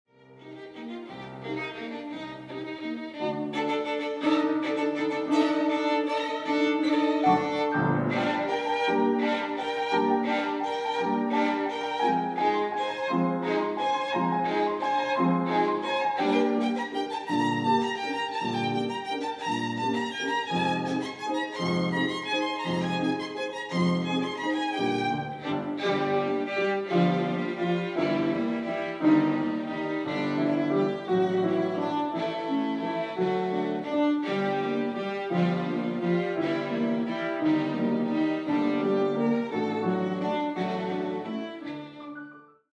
Musical Performances